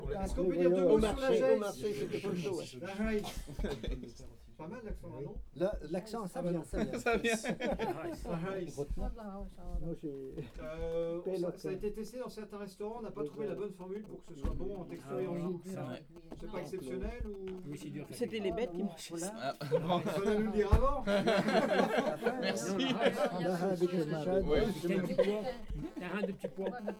Atelier de collectage de témoignages sur les légumes traditionnels du Marais-Breton-Vendéen
Catégorie Témoignage